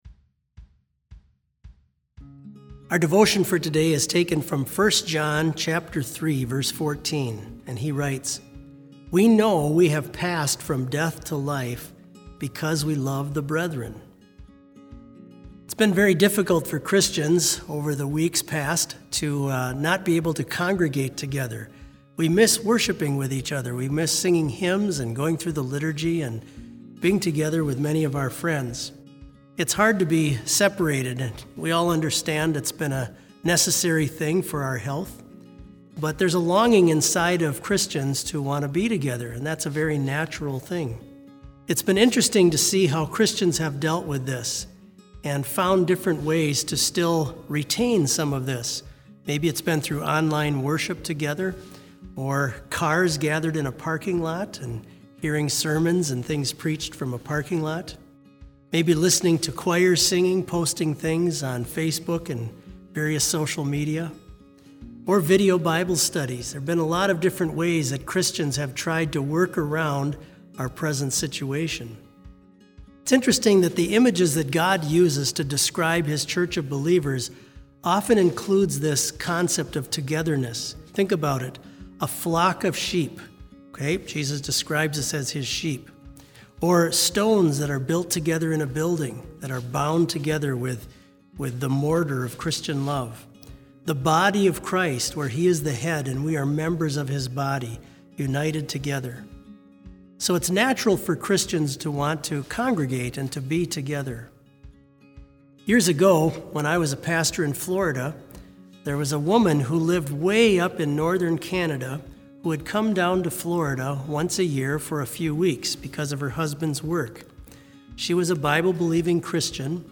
Complete service audio for BLC Devotion - April 22, 2020